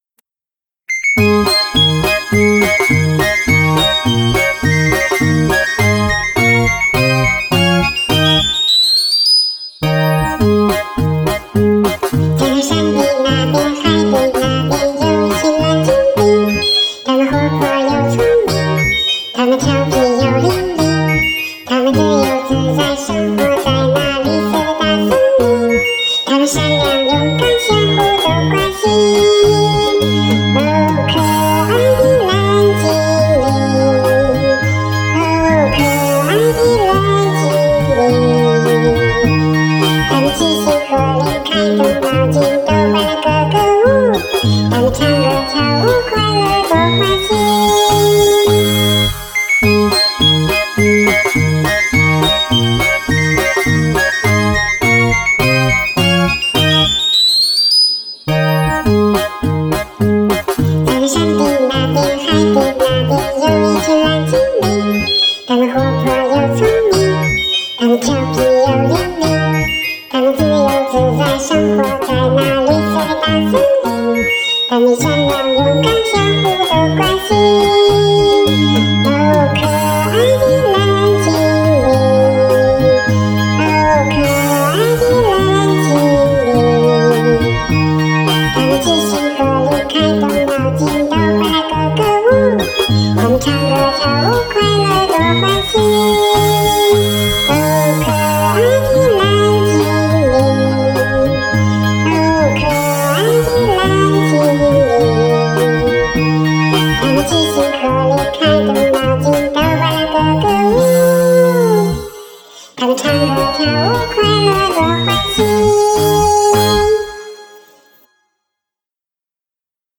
这是自己唱的啊，我来听听
变音了，感觉变的不是很多，但是也听不出来
变音还能听出来就太神了